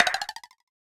offbeatLightbulbOut.wav